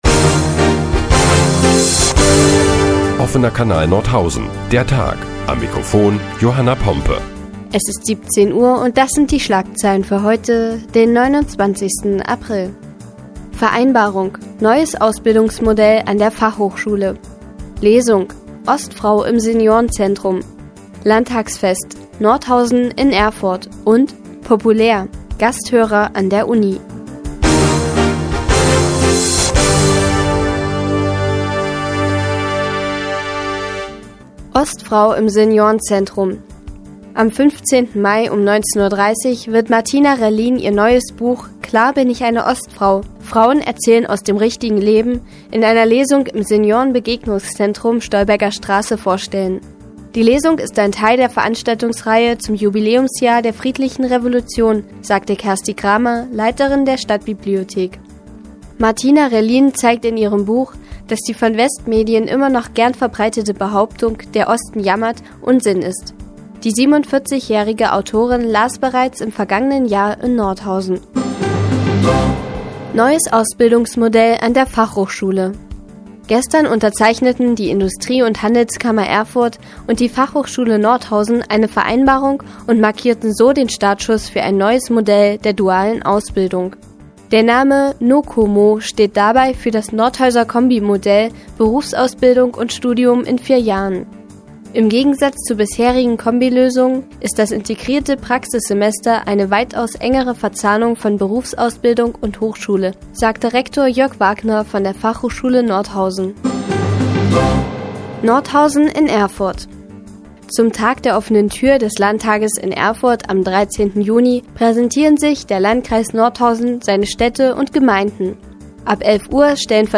Die tägliche Nachrichtensendung des OKN ist nun auch in der nnz zu hören. Heute geht es unter anderem um ein neues Ausbildungsmodell an der Fachhochschule und eine Buchlesung im Seniorenbegegnungszentrum.